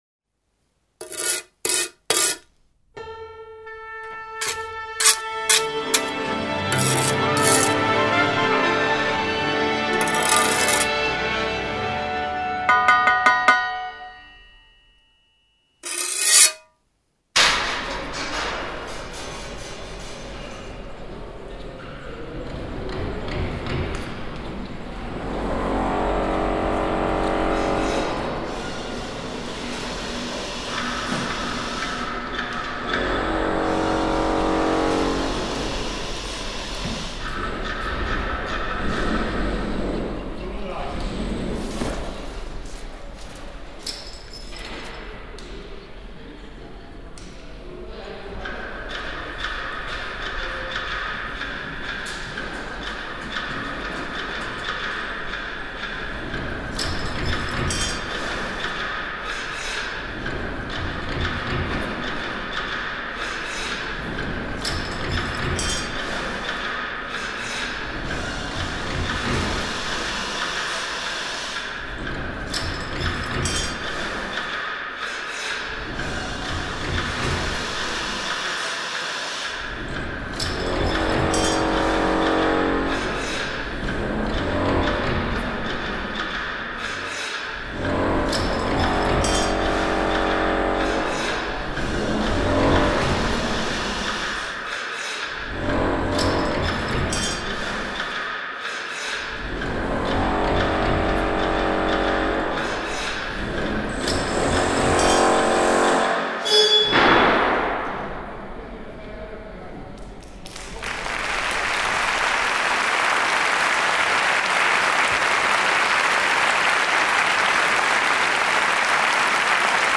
In diesem Experimentierfeld bewegen sich die hier vorgestellten “akustischen Skizzen”. Es sind Experimente – mal näher angelehnt an die Musik, mal mehr als Versuche mit dem konkreten Geräusch.
Die Aufnahmen zu “Symphonischer Nachmittag” entstanden auf einer Großbaustelle in der Nähe von Oldenburg.